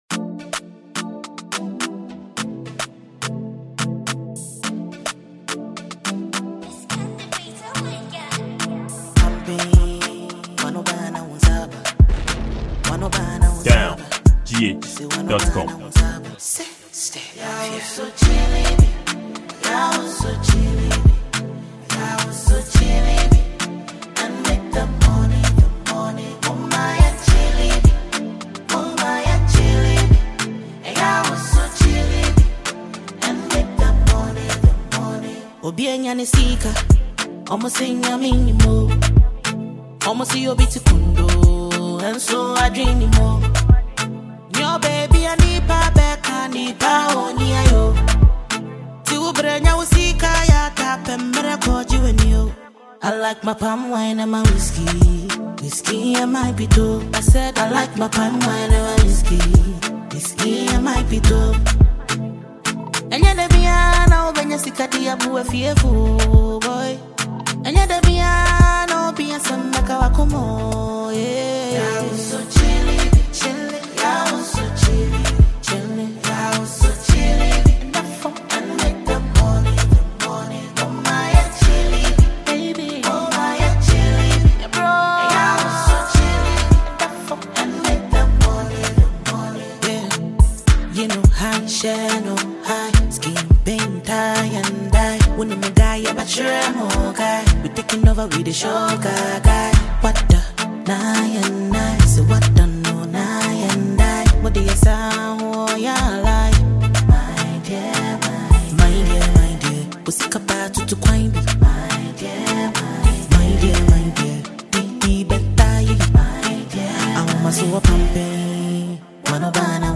Genre: Highlife/Afrobeat